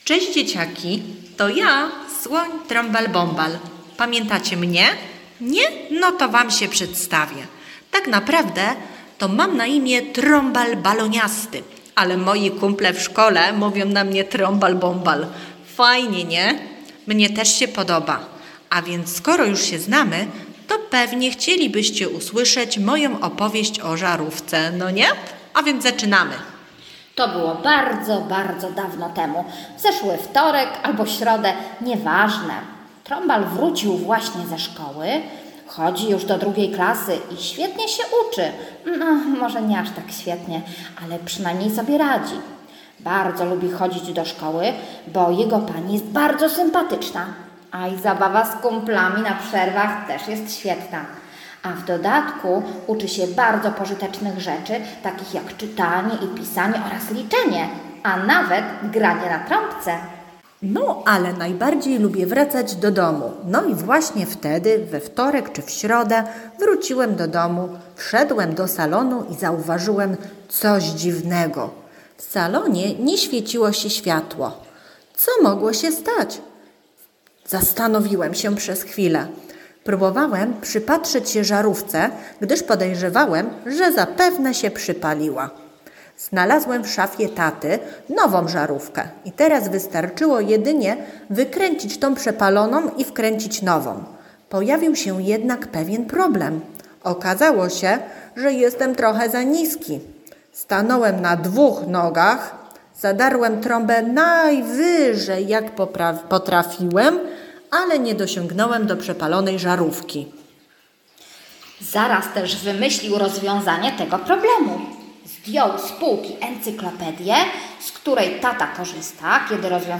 Oto nowa bajka z cyklu o Trąbalu Bąbalu, wesołym, przyjacielskim słoniu. Tym razem słonik będzie próbował zmienić przepaloną żarówkę. Nagrałyśmy dla Was tę bajkę z okazji Międzynarodowego Dnia Postaci z Bajek.